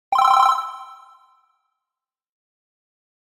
SE（魔法 着信音）
チャラララ。魔法。着信音。